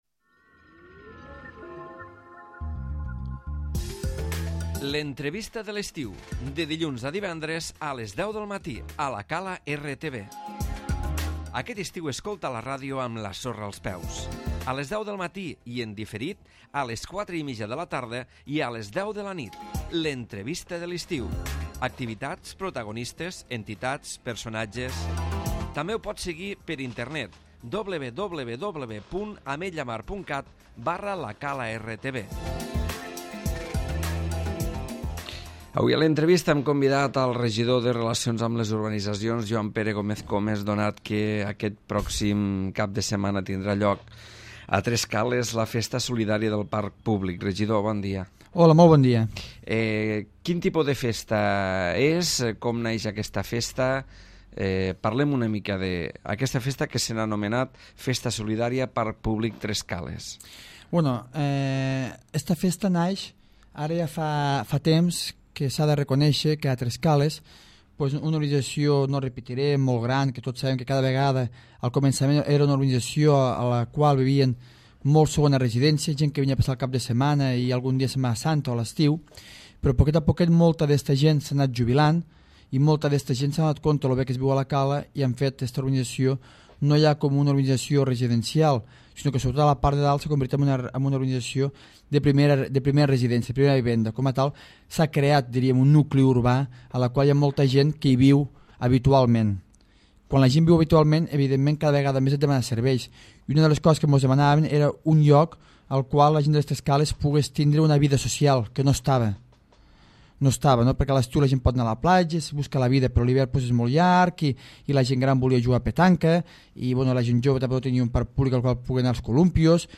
L'Entrevista
El regidor de relacions amb les urbanitzacions, Joan Pere Gómez Comes, ha estat present avui a l'entrevista per parlar de la II Festa Solidària del Parc Públic de Tres cales on hi ha organitzat un ampli programa d'activitats.